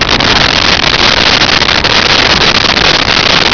Sfx Amb Machine Loop
sfx_amb_machine_loop.wav